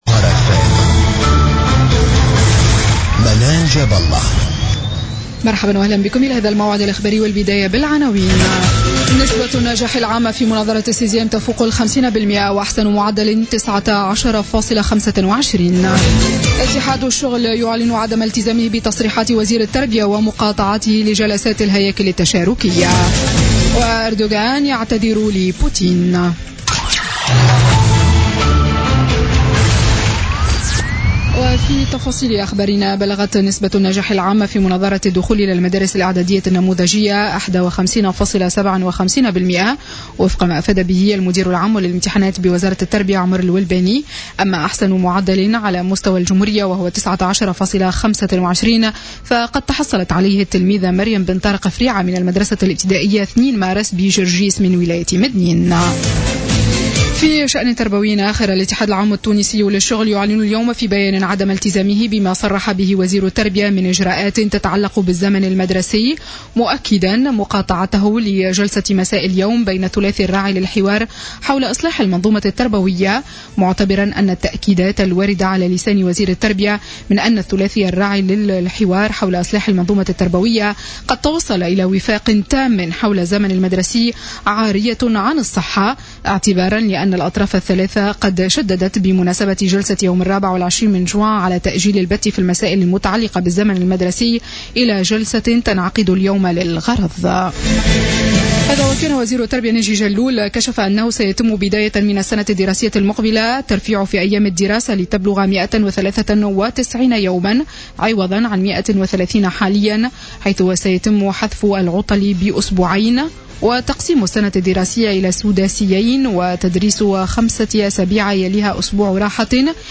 نشرة أخبار الخامسة مساء ليوم الإثنين 27 جوان 2016